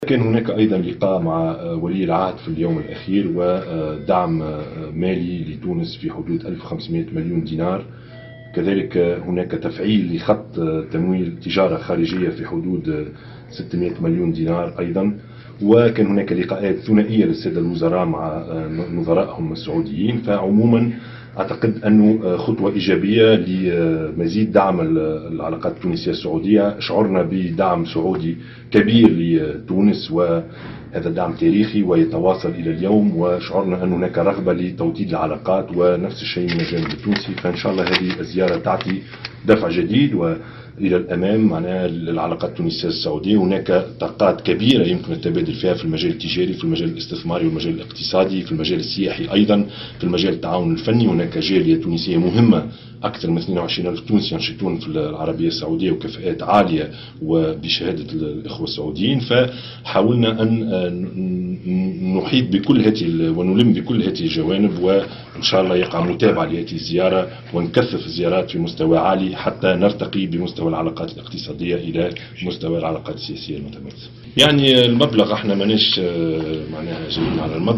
وقال الشاهد في تصريحات اعلامية على هامش اليوم الأخير من زيارة رسمية يؤديها للمملكة العربية السعودية منذ الخميس الماضي، إن المساعدات السعودية شملت أيضا خط تمويل التجارة الخارجية التونسية بنحو 600 مليون دينار مما سيعمل على دفع الصادرات التونسية، بالاضافة الى اتفاقات مالية لمشاريع تنموية تم توقيعها منذ اليوم الأول لزيارته بقيمة 350 مليون دينار.